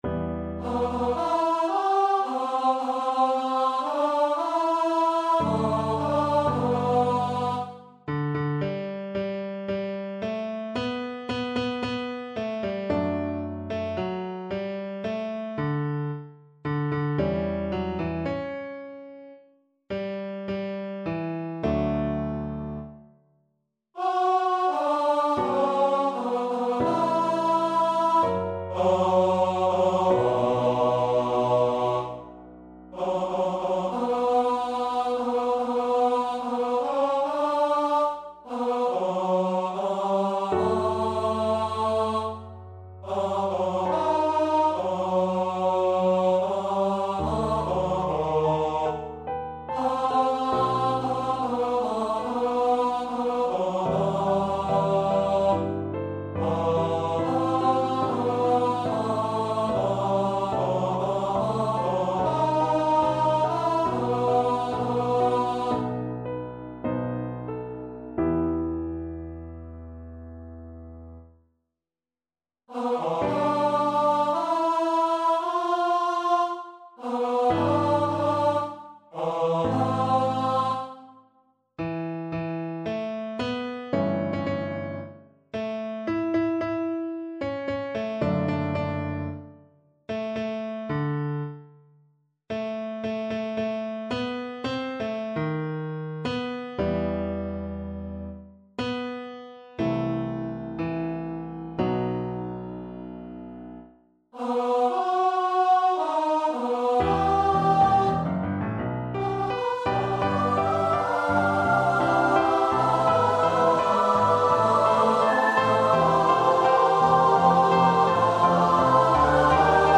Und der Hohepriester; Er ust des Todes schuldig (St. Matthew Passion) Choir version
Choir  (View more Intermediate Choir Music)
Classical (View more Classical Choir Music)